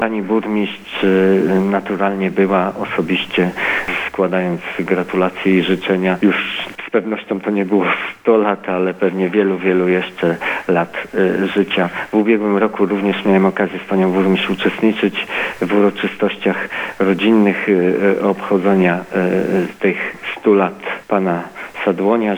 mówi Robert Betyna, zastępca burmistrz Pyrzyc.